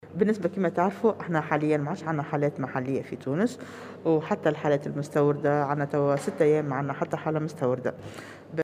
وأضافت بن علية في تصريح اليوم لمراسلة "الجوهرة أف أم" على هامش زيارتها إلى جزيرة جربة أن تونس تمكنت إلى حدّ الأن من السيطرة على هذا الوباء، لكن الحذر يبقى قائما